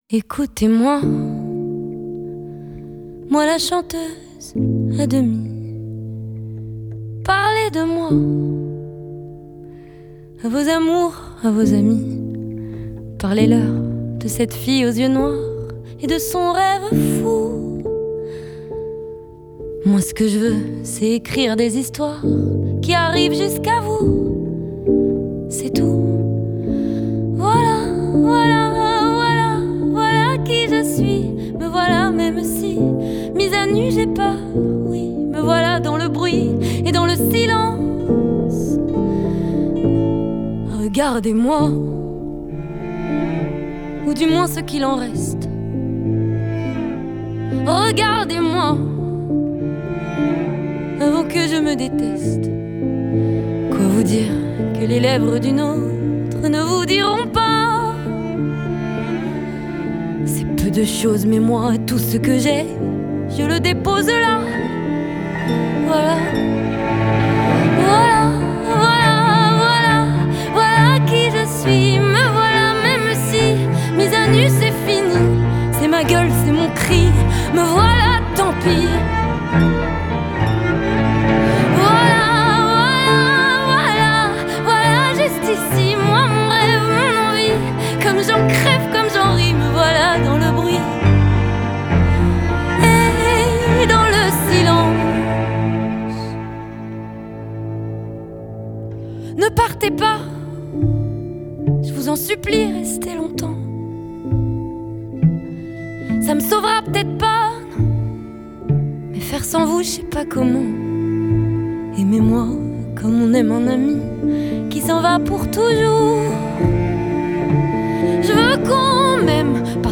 французского шансон